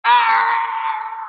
yoda death noise